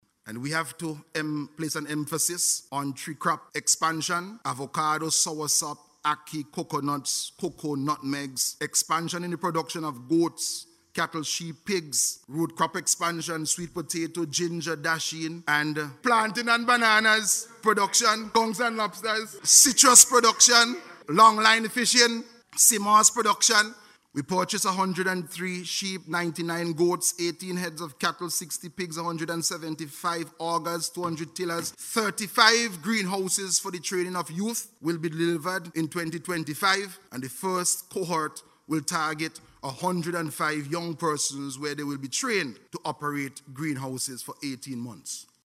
Minister Caesar made this statement while making his contribution to the 2025 budget debate recently.